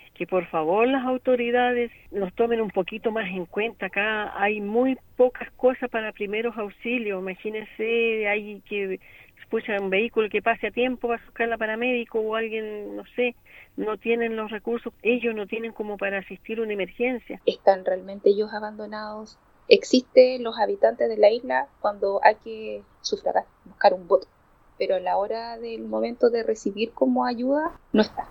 En conversación con Radio Bío Bío, los habitantes de Isla Huapi expresaron su sensación de abandono ante situaciones de emergencia y pidieron a las autoridades locales disponer de recursos e implementos que permitan reducir los tiempos de respuesta en casos críticos.